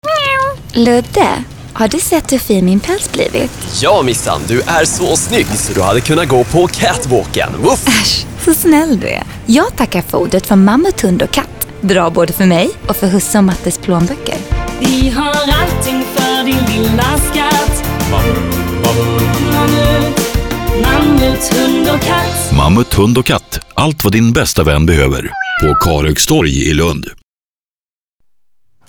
Kommerziell, Natürlich, Verspielt, Warm, Corporate
Unternehmensvideo